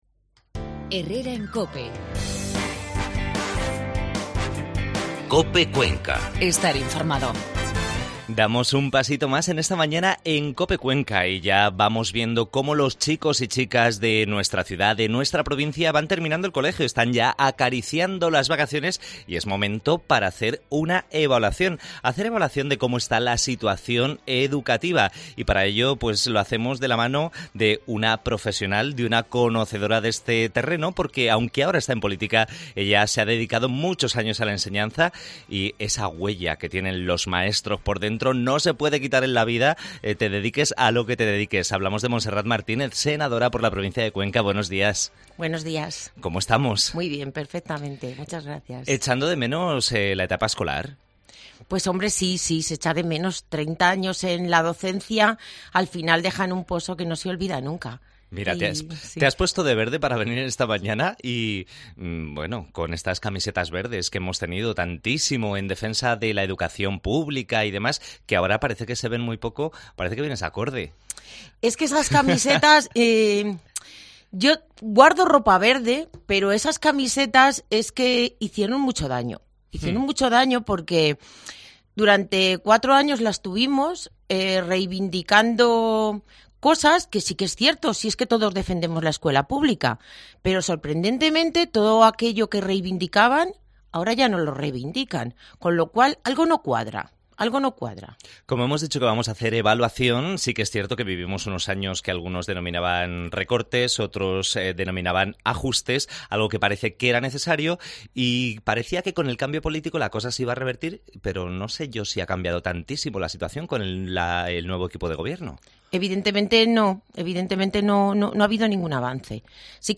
AUDIO: Entrevista a la Senadora del PP Montserrat Martinez